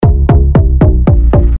Ёмкий стучащий звук для сообщения 469 00:01
Стук